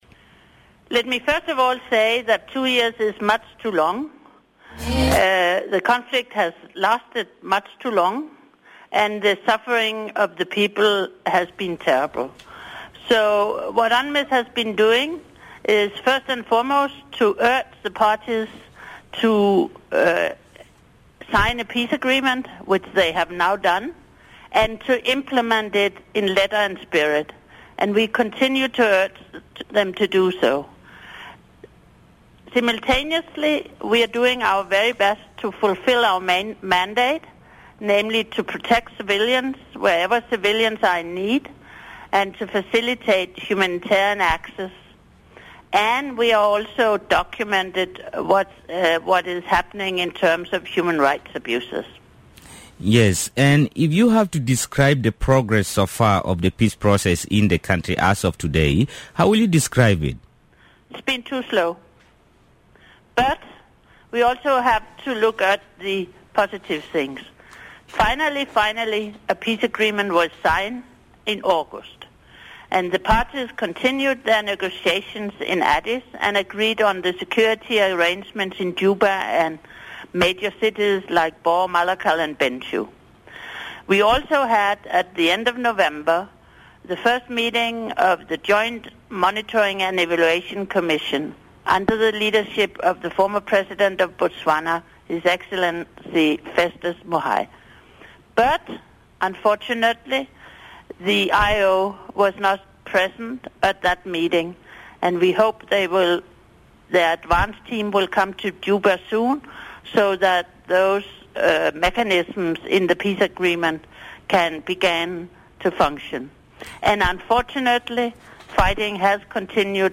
Speaking in an exclusive interview with Radio Miraya, the SRSG said stakeholders in the peace process should cast aside the obstacles to peace and pave way for development in the country.